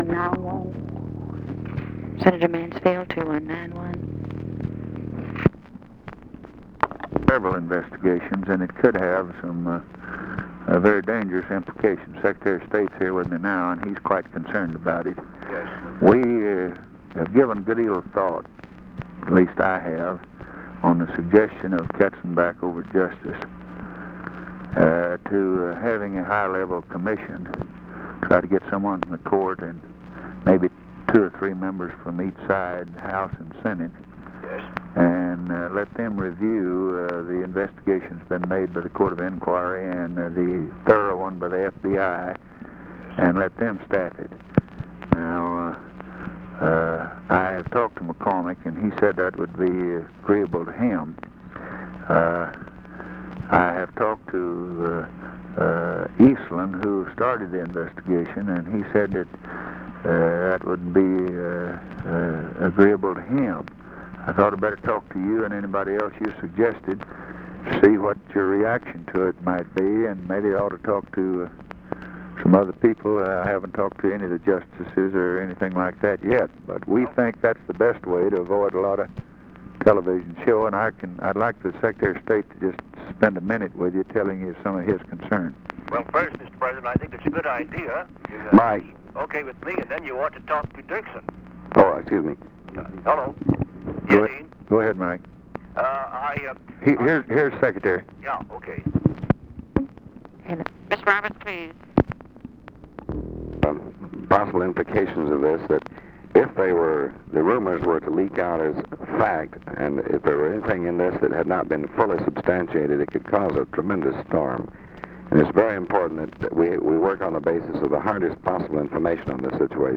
Conversation with MIKE MANSFIELD, November 29, 1963
Secret White House Tapes